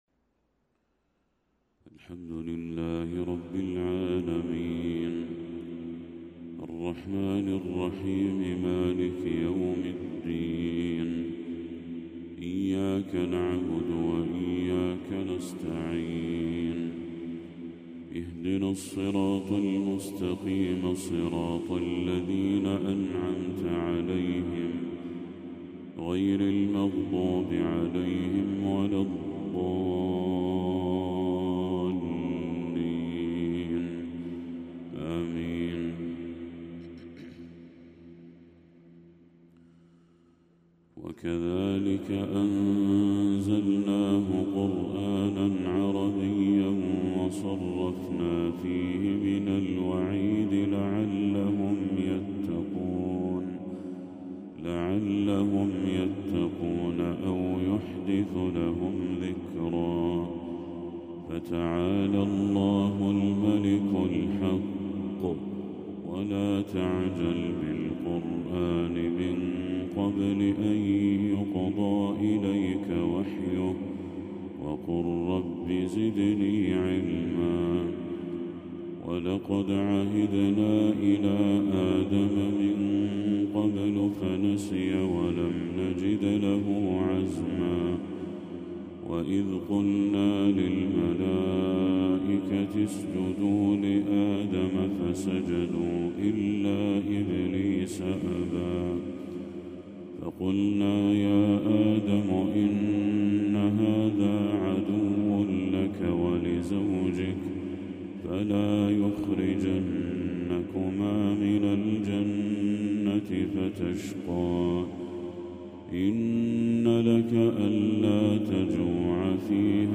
تلاوة ندية لخواتيم سورة طه للشيخ بدر التركي | فجر 29 ربيع الأول 1446هـ > 1446هـ > تلاوات الشيخ بدر التركي > المزيد - تلاوات الحرمين